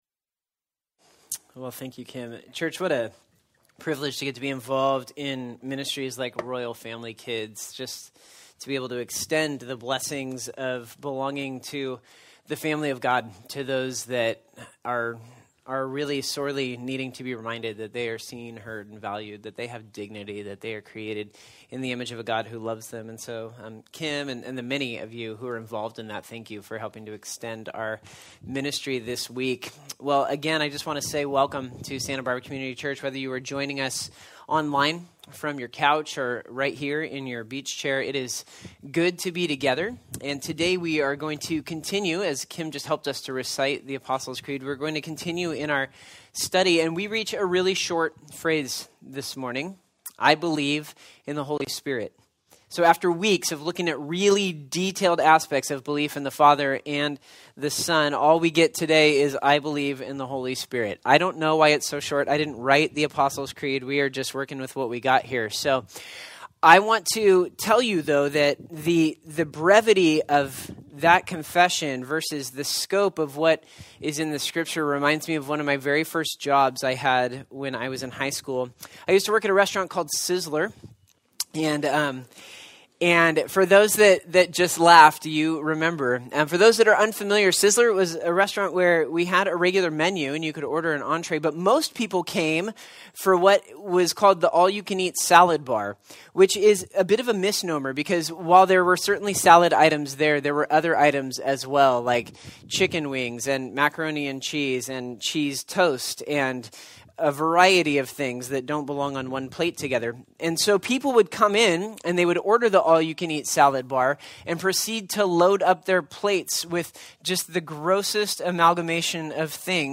Galatians 5:13-26 Service Type: Sunday Topics